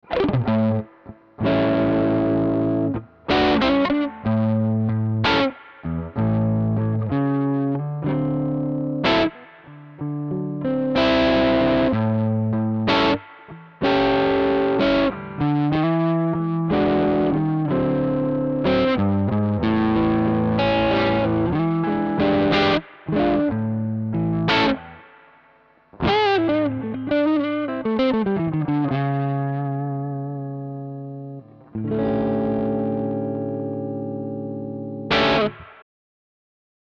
Die Roswell LAF 5 Humbucker liefern in Kombination mit dem Mahagoni Holz sehr schöne Sounds.
Harley Benton EX-76 Classic GHW AN Soundbeispiele
Ich habe für alle Beispiele meinen Mesa Boogie Mark V 25 mit dem CabClone D.I. verwendet.